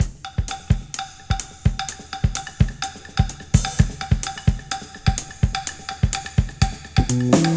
• 127 Bpm Drum Beat E Key.wav
Free drum beat - kick tuned to the E note.
127-bpm-drum-beat-e-key-8kO.wav